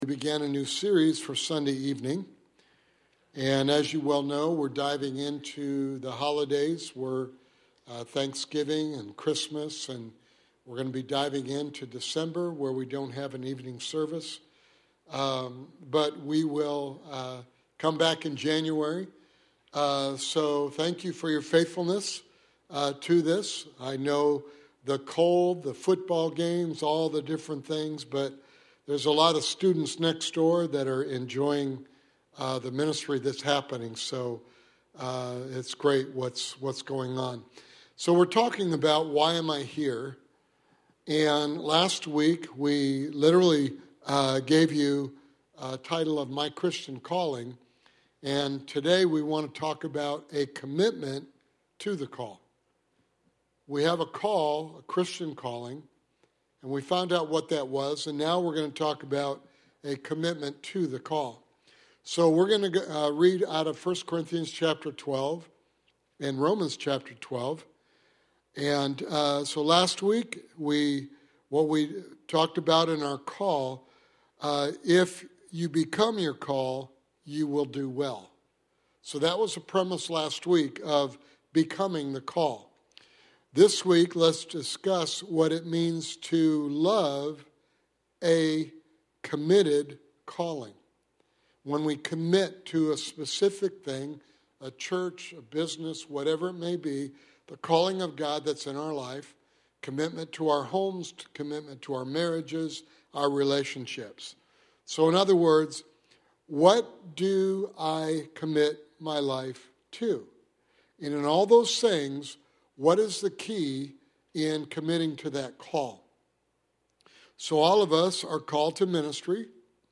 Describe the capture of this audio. Sermon Series: Why Am I Here? Sunday evening Bible study